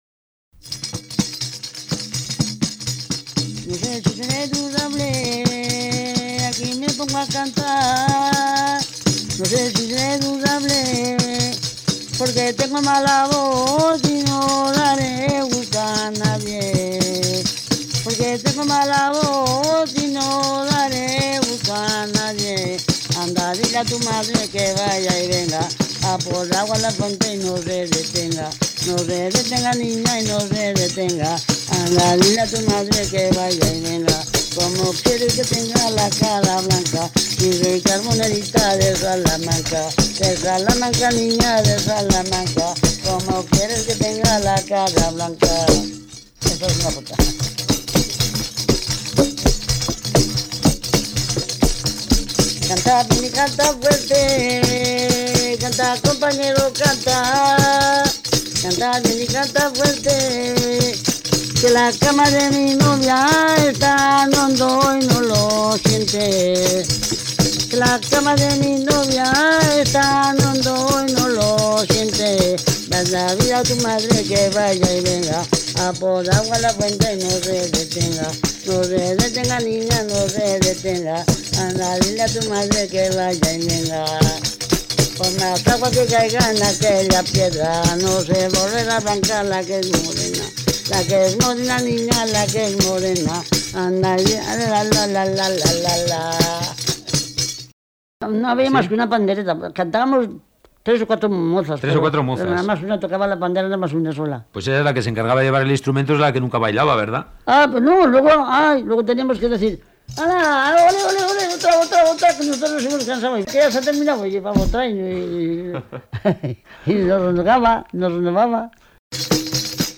Clasificación: Cancionero
Localidad: Neila
Lugar y fecha de recogida: Neila, 8 de mayo de 1986
Pandera.mp3